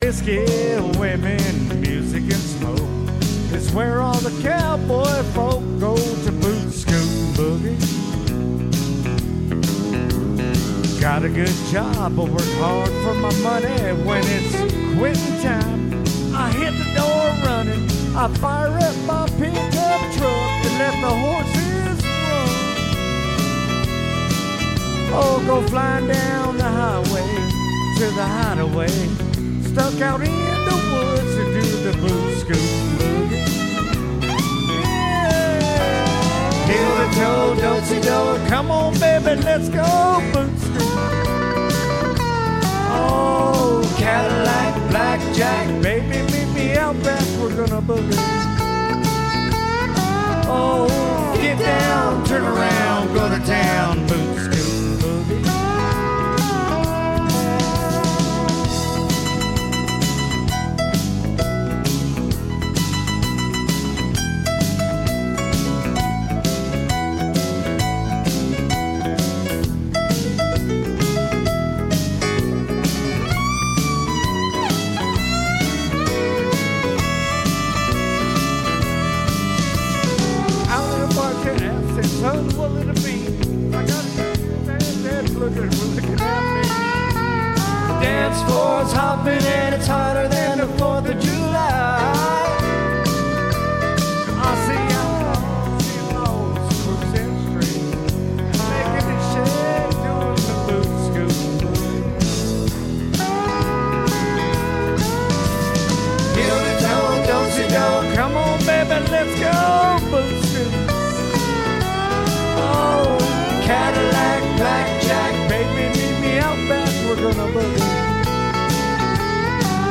Karaoke, live bands, Denver Colorado, Mile High Karaoke